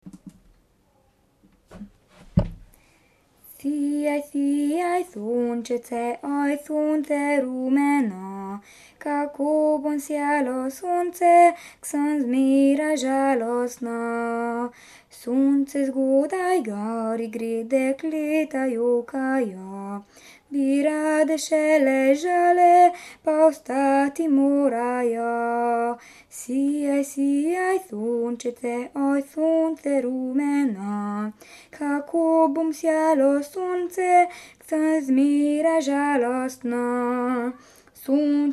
533 - Music
3.7. - PEVSKA KULTURA